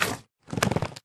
Sound / Minecraft / mob / magmacube / jump3.ogg
jump3.ogg